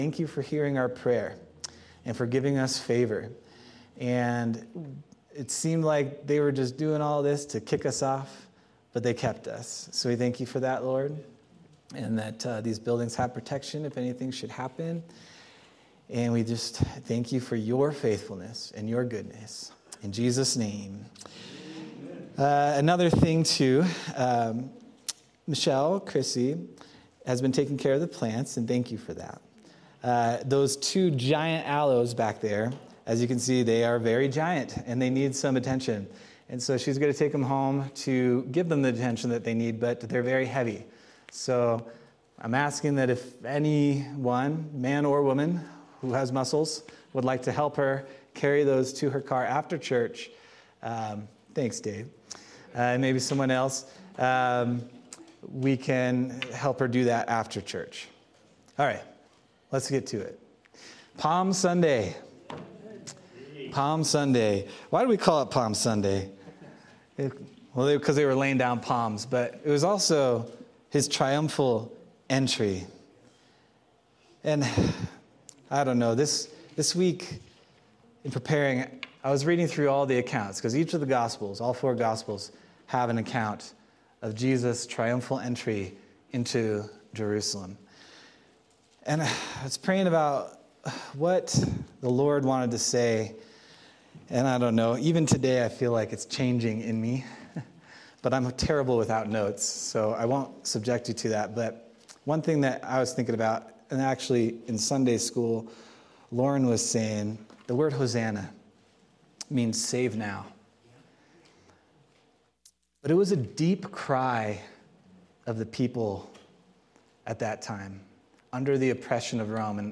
April 13th, 2025 Sermon